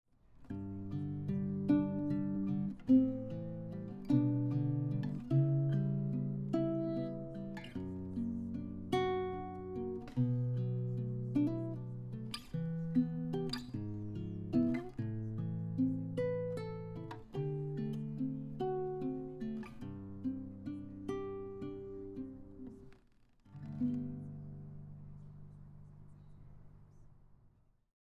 I ended up getting the Yamaha CGS102A, a half size nylon string acoustic guitar that has a clean, classic look with a great sound at a very reasonable price.
What I liked about the guitar is the full, warm and clean sound.
I’m using a Focusrite Scarlett audio interface to record these examples.
Finger picking audio example
CGS102A-finger-picking.mp3